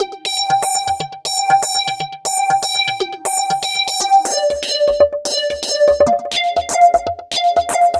Session 08 - Pop Arpeggiation.wav